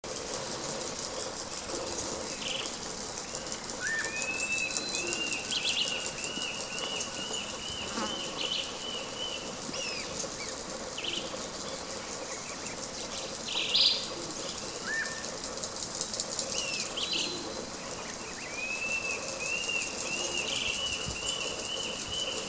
Macuru-de-barriga-castanha (Notharchus swainsoni)
Nome em Inglês: Buff-bellied Puffbird
Localidade ou área protegida: Reserva de Biosfera Yabotí
Condição: Selvagem
Certeza: Gravado Vocal